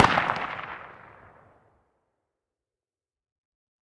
svdveryfar.ogg